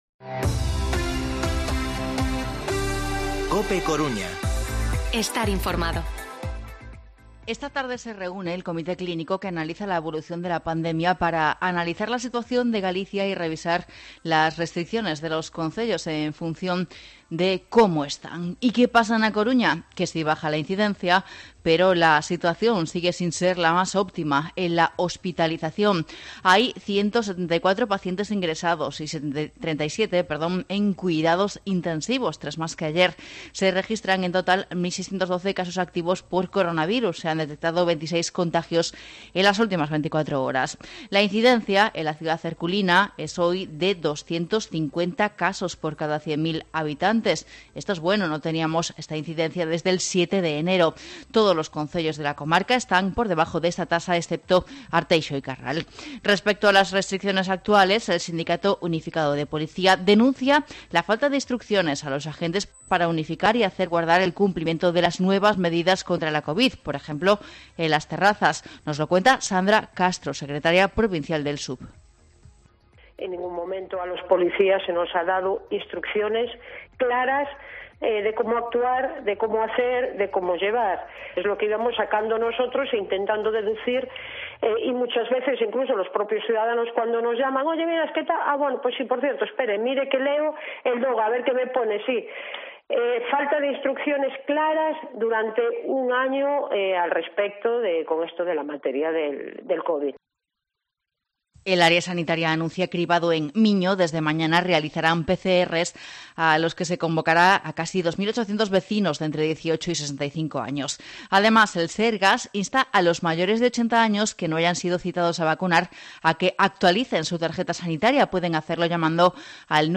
Informativo mediodía COPE Coruña 2/03/2021 De 14:20 a 14:30 horas